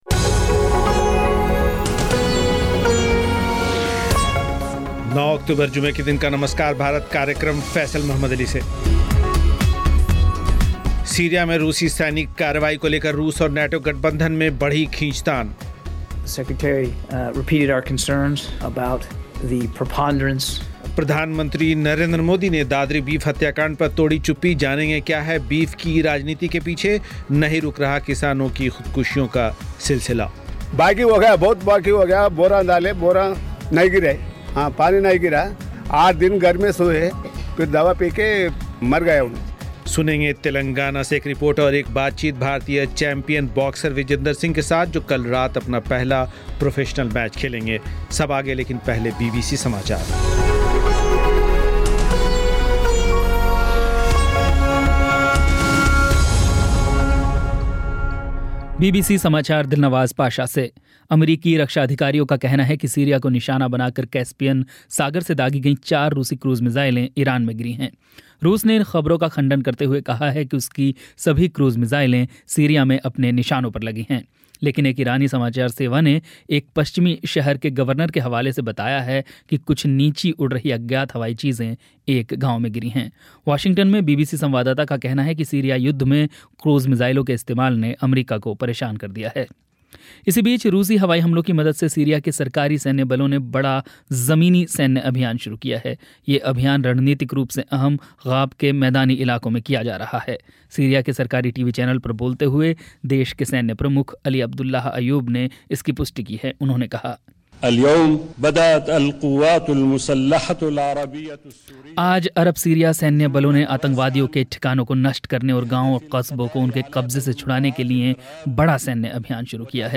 एक रिपोर्ट तेलंगाना से और, एक बातचीत भारतीय चैंपियन बाक्सर विजेंद्र सिंह के साथ